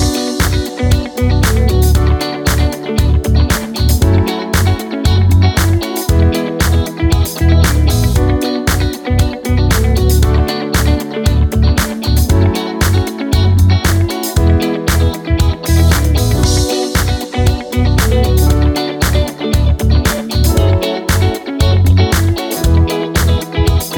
Minus Piano Pop (2010s) 4:17 Buy £1.50